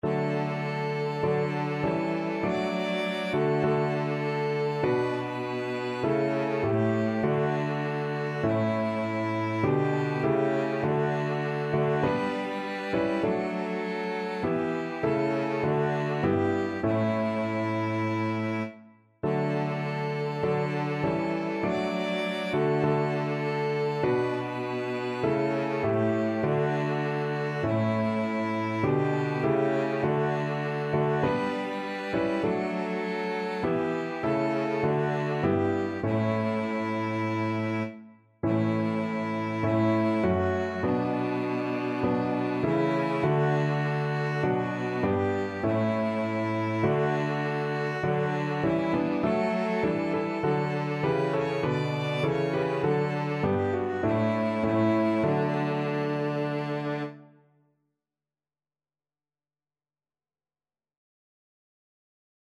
ViolinCelloPiano
Maestoso
Piano Trio  (View more Easy Piano Trio Music)
Christmas (View more Christmas Piano Trio Music)